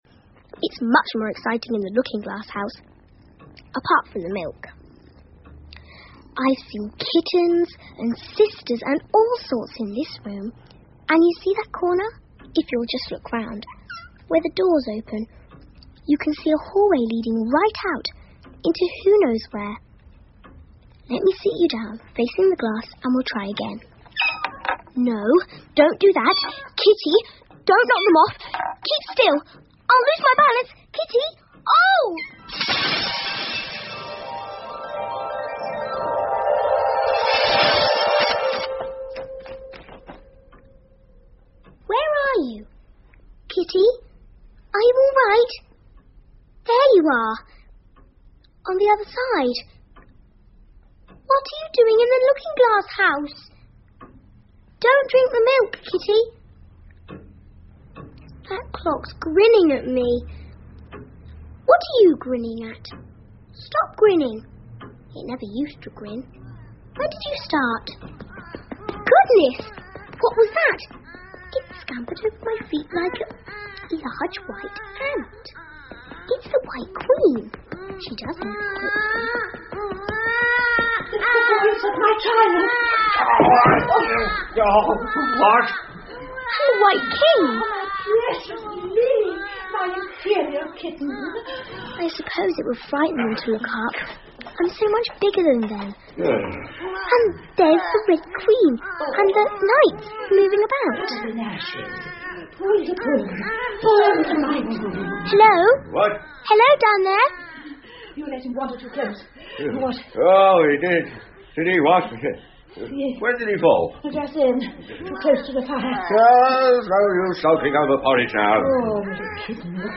Through The Looking Glas 艾丽丝镜中奇遇记 儿童广播剧 2 听力文件下载—在线英语听力室